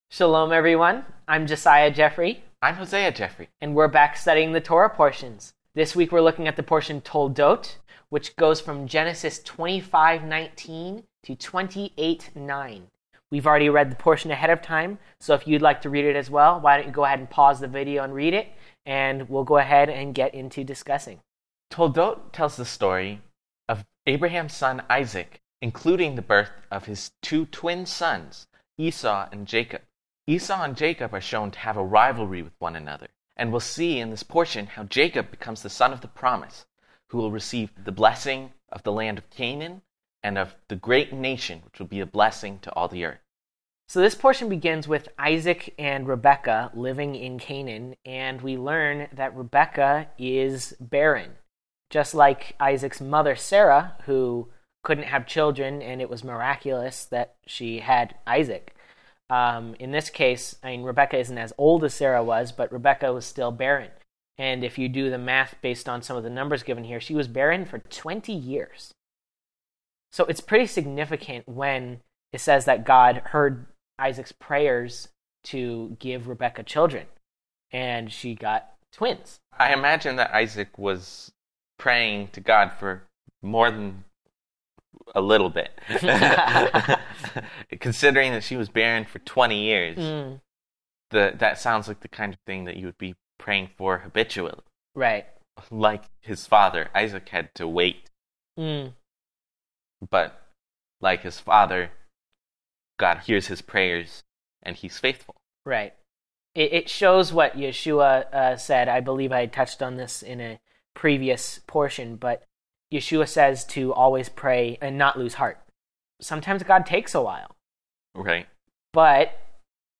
Messianic Jewish Teacher